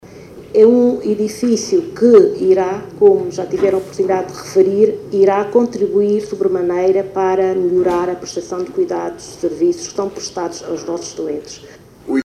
O acto ocorreu quarta-feira nas instalações do hospital Dr. Ayres de Menezes, nas imediações da Cidade de São Tomé e foi presidido pela titular da pasta de Saúde, Maria de Jesus Trovoada.